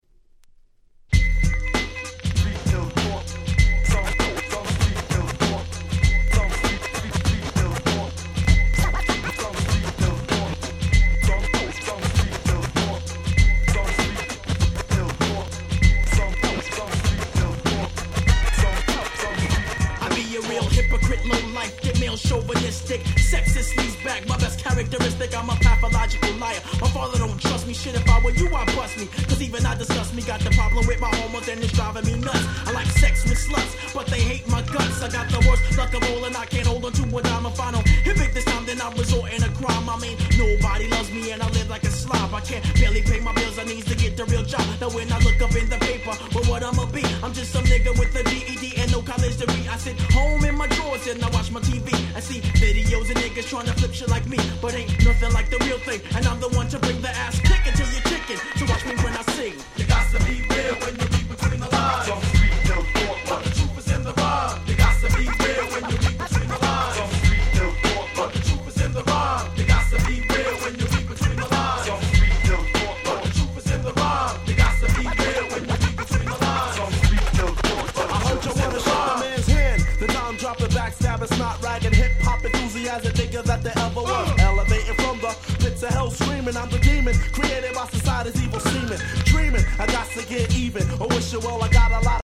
94' Nice Hip Hop !!